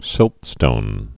(sĭltstōn)